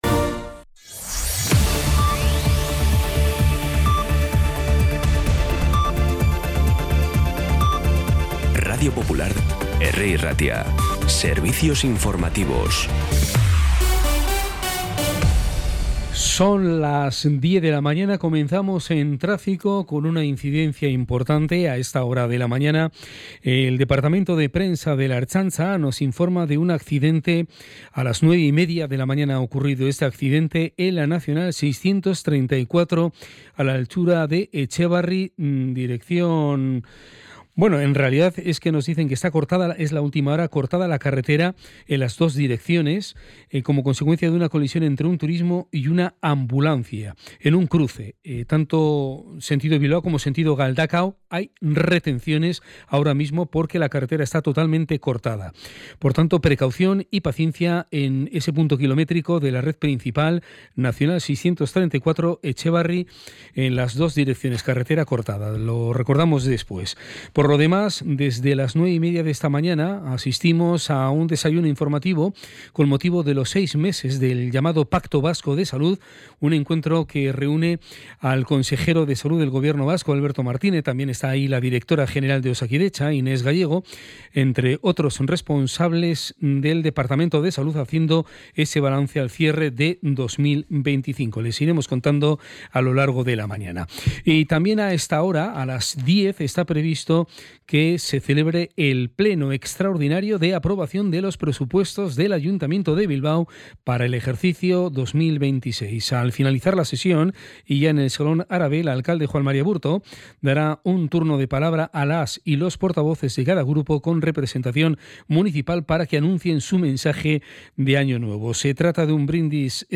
Las noticias de Bilbao y Bizkaia del 29 de diciembre a las 10
Los titulares actualizados con las voces del día. Bilbao, Bizkaia, comarcas, política, sociedad, cultura, sucesos, información de servicio público.